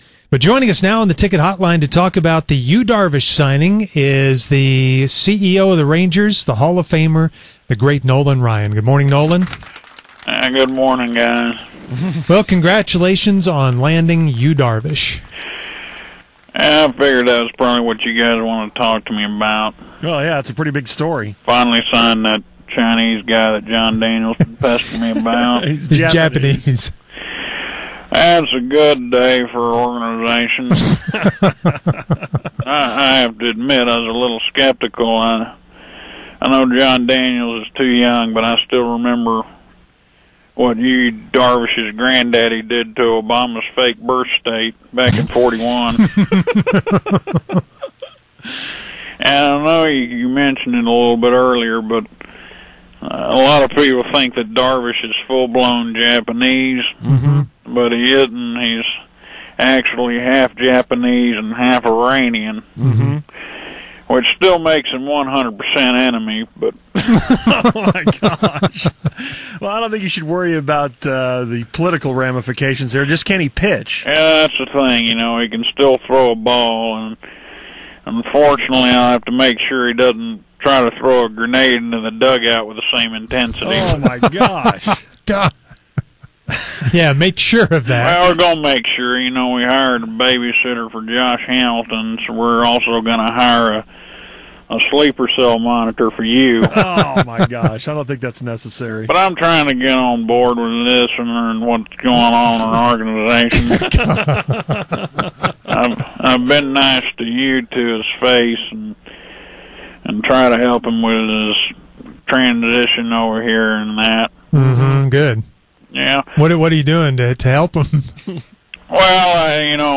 At 8:40 today, fake Nolan calls in to talk about the Yu Darvish signing. He’s excited about it, even though you can’t tell, and then reveals his real reason why he’s excited to have Yu on the team…